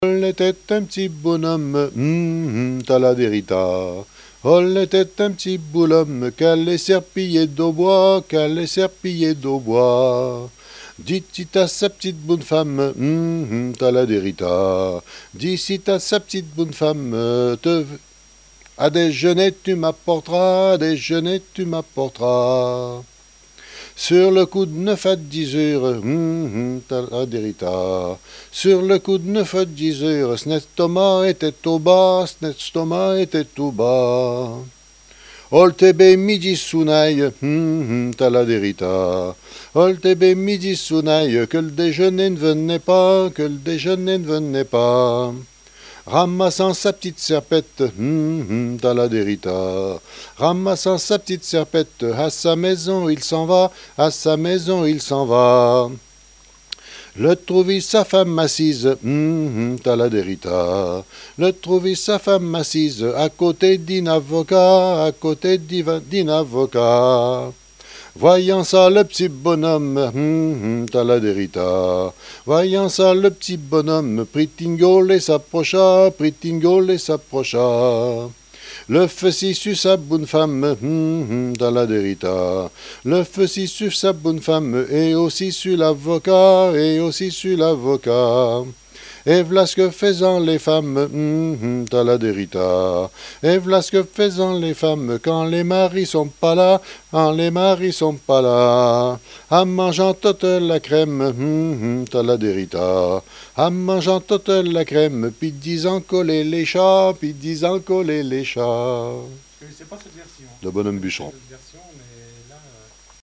Genre laisse
répertoire de chansons, de danses et fables de La Fontaine
Pièce musicale inédite